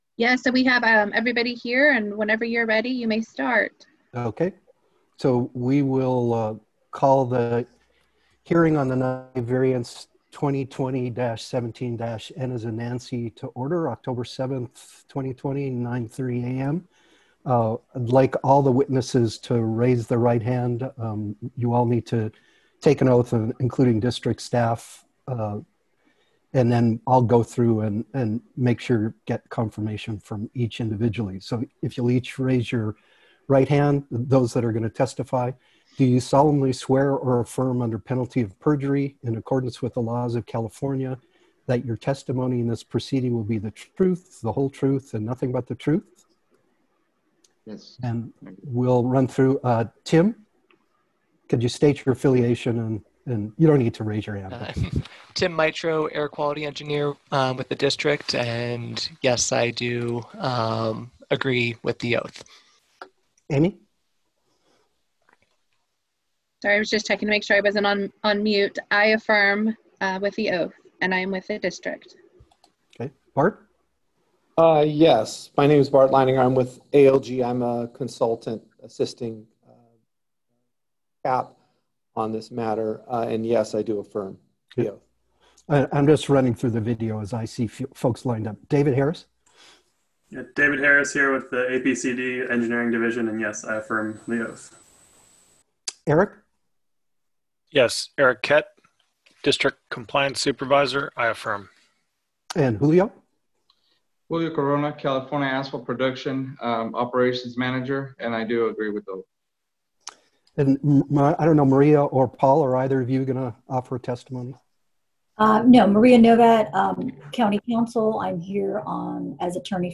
APCD Hearing Board Agenda – October 7, 2020 – Santa Barbara County Air Pollution Control District